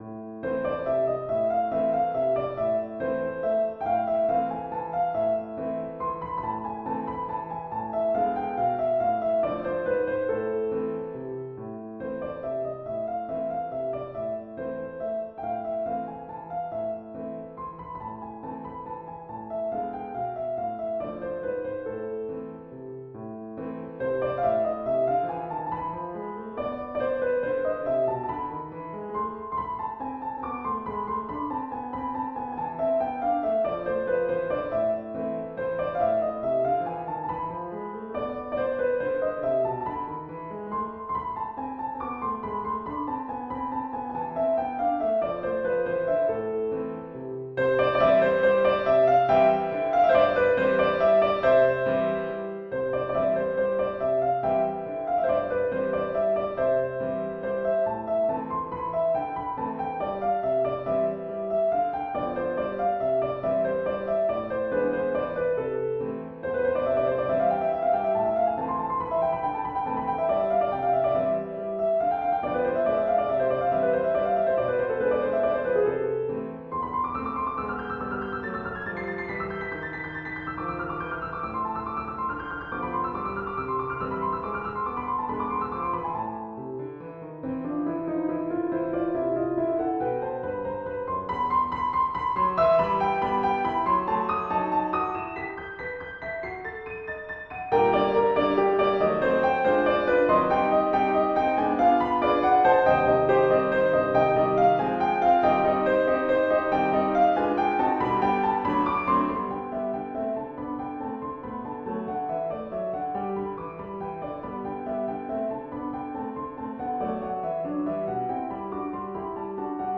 waltz for piano in a-minor
waltz_for_piano.mp3